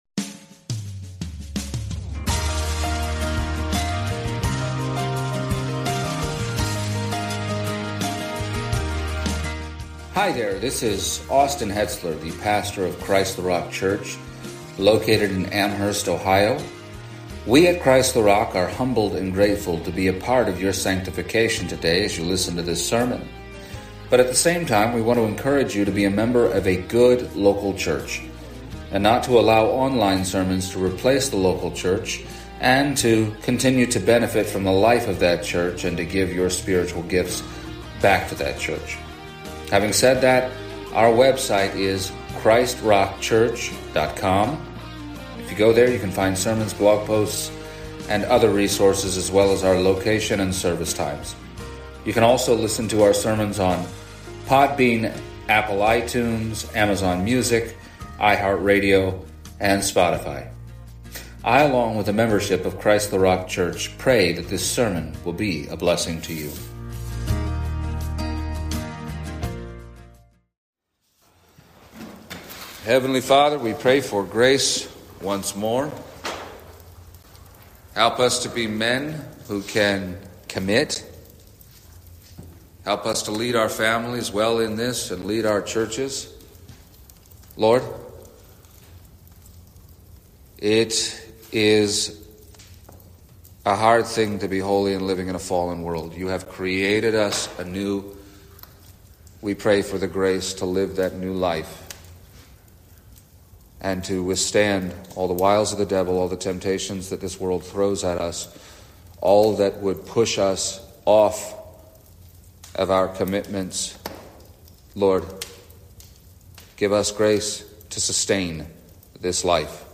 The second of three messages given at the 2025 CtRC Men’s Summit